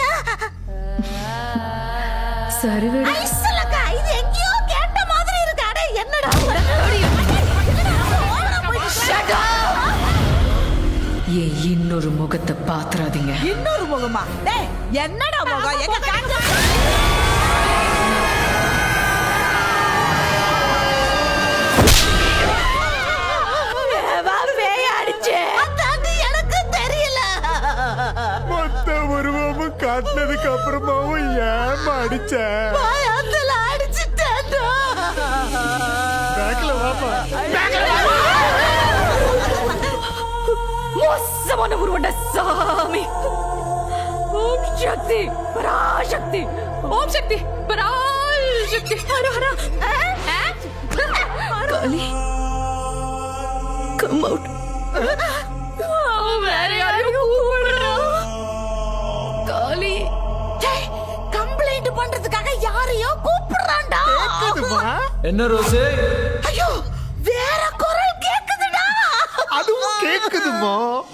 music
tamil-audio-emotion-classification / final_data /fear /audio_25.wav